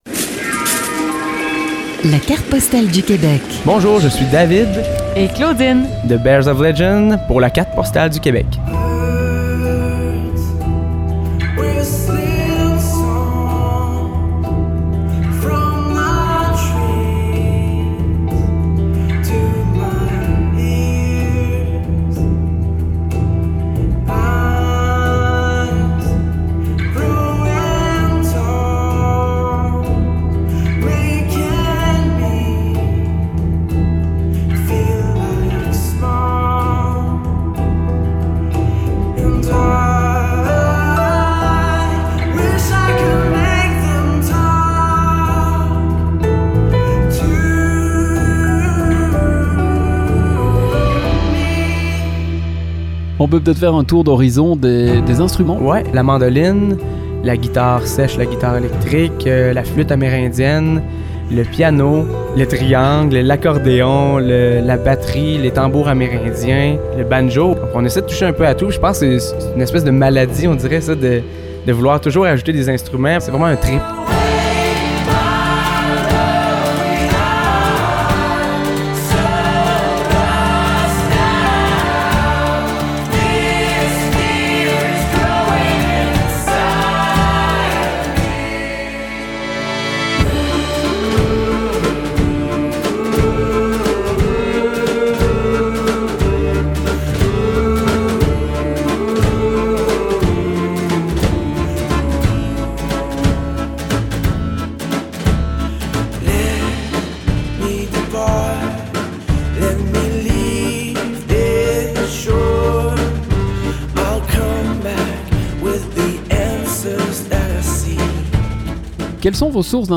7 artistes musiciens, perfectionnistes, originaires de la région de la Mauricie forment le groupe Bears of Legend.
Les morceaux d’accordéon nous ont ramenés dans l’univers d’Amélie Poulain, de Yann Tiersen
Version album ou version live, même sonorité, même plaisir.
chanteur, flûte, mandoline et guitare.
guitare et percussions.
batterie.
violoncelle.
accordéon, xylophone et percussions amérindiennes.
basse.